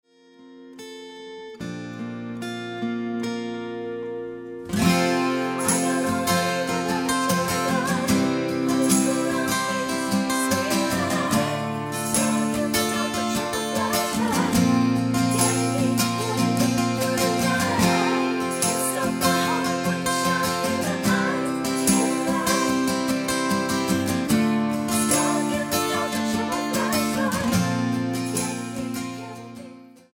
Tonart:F mit Chor